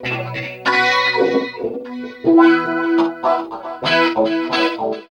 69 GTR 2  -R.wav